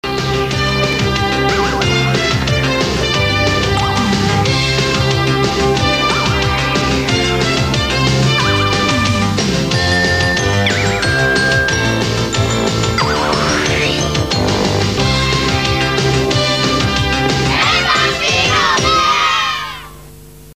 Titelmelodie